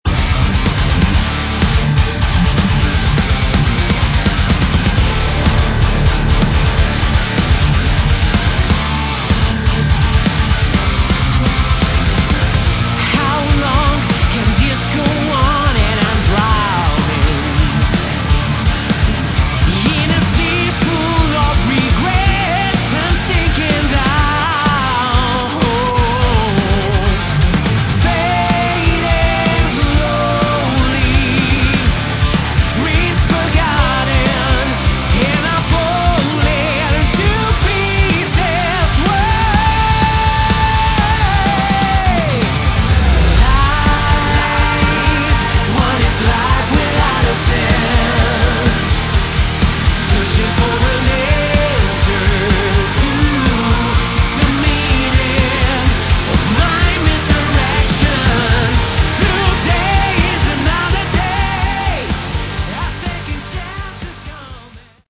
Category: Melodic Metal / Prog Metal
vocals
guitars
bass
drums
keyboards